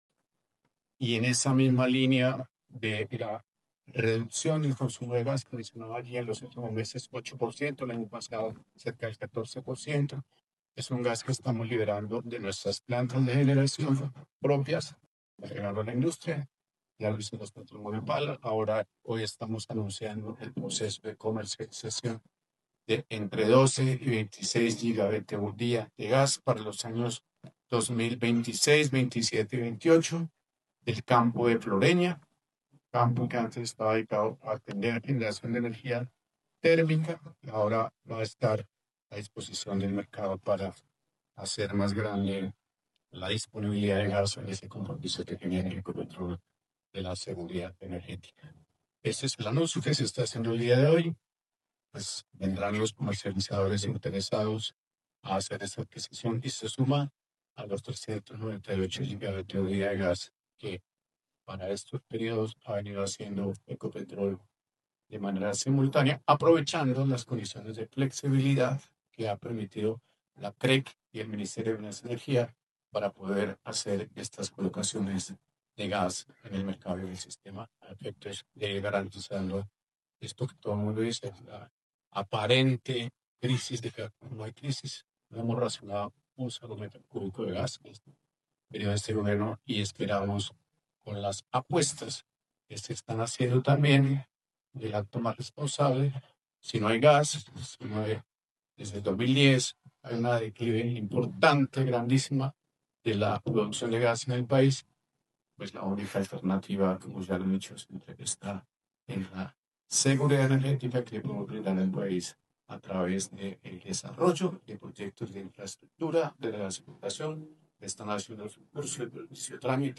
Ricardo Roa, presidente de Ecopetrol